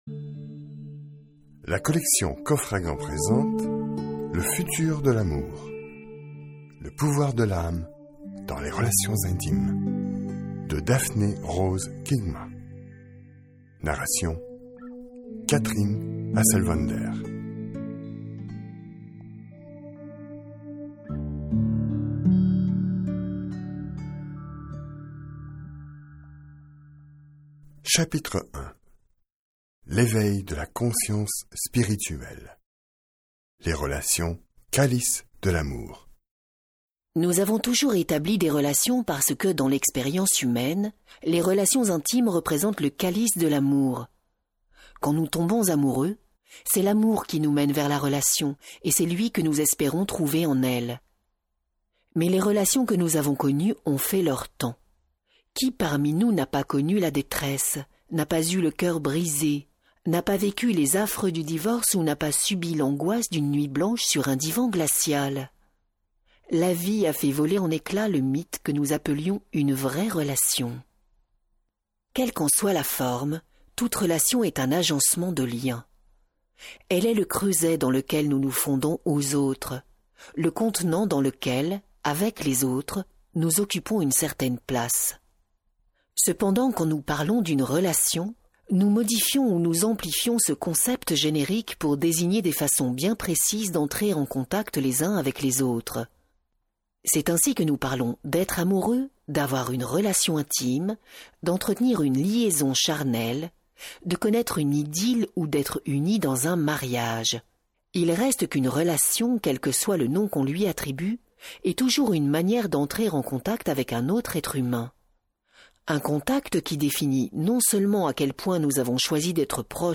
Diffusion distribution ebook et livre audio - Catalogue livres numériques
Lire un extrait - Le futur de l'amour de Daphne Rose Kingma